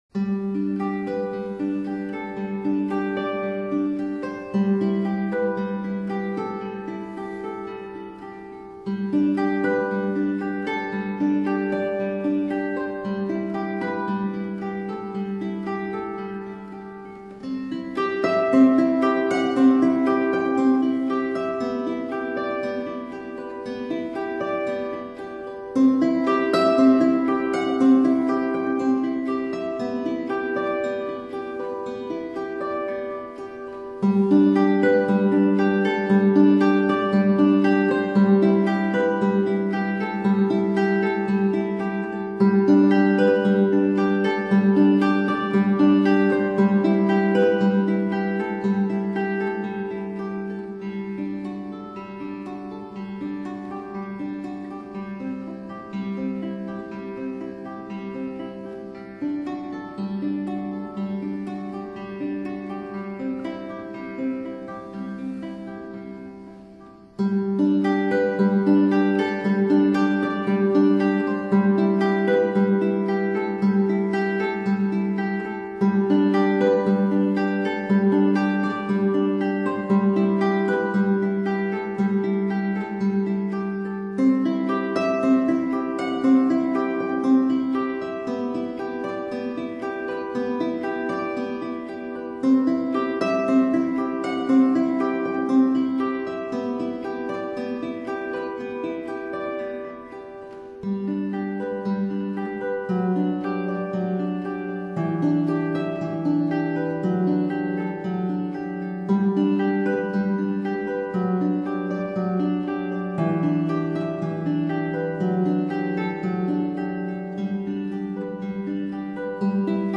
triple Baroque harp.